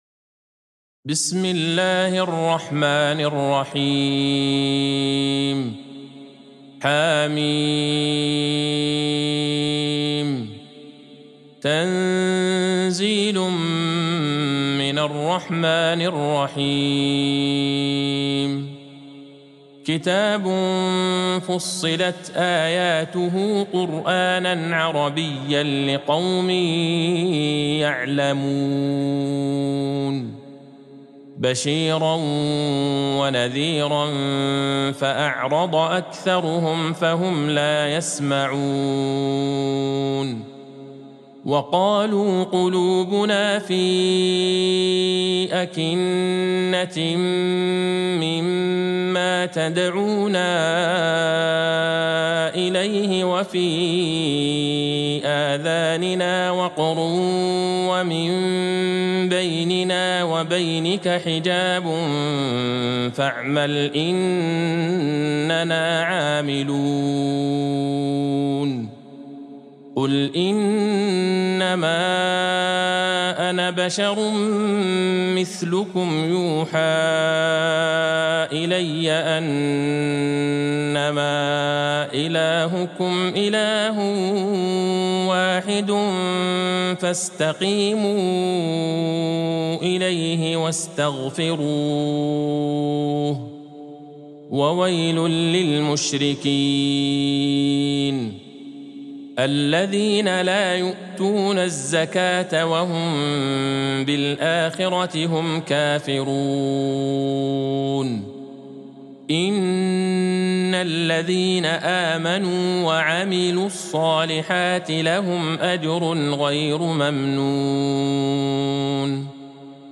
سورة فصلت Surat Fussilat | مصحف المقارئ القرآنية > الختمة المرتلة ( مصحف المقارئ القرآنية) للشيخ عبدالله البعيجان > المصحف - تلاوات الحرمين